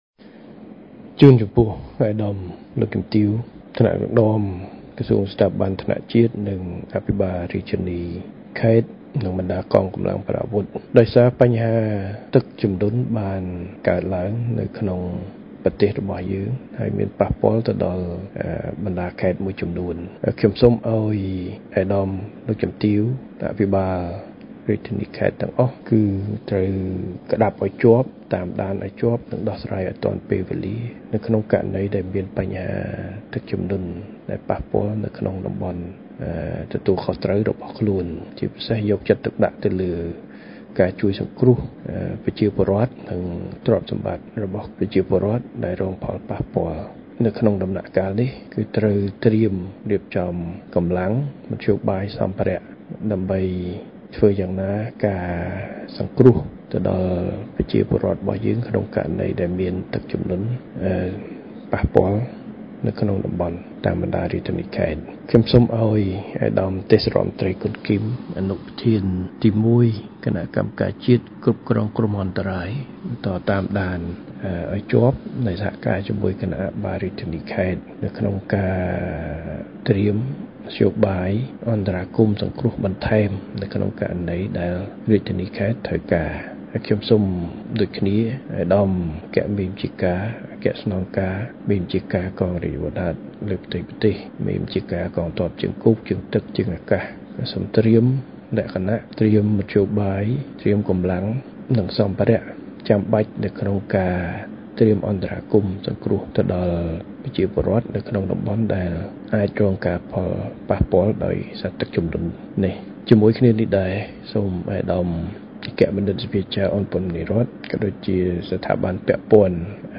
សំឡេងសម្ដេចធិបតី ហ៊ុន ម៉ាណែត៖